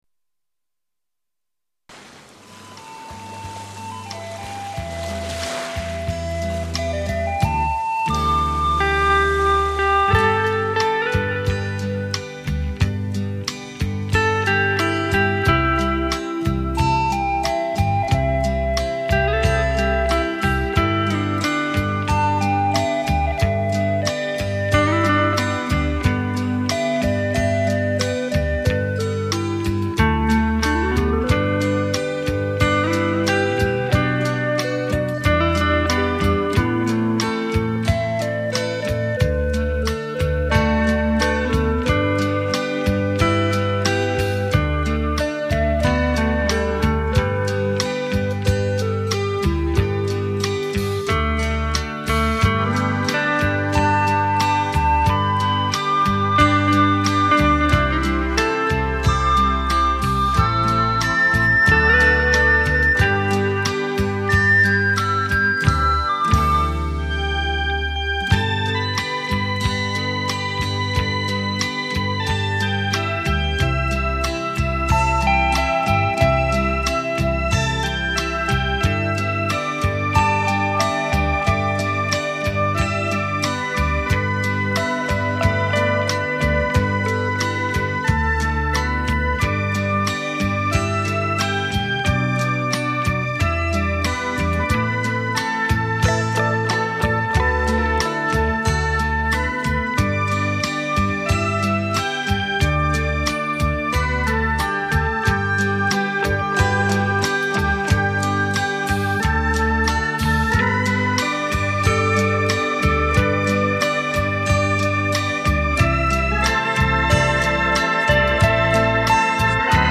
日本民谣大多清淡，单纯，发声自然，内容有的抒发爱情，有的描绘风景，有的反映劳动生活，有的反映风俗习惯和社会生活。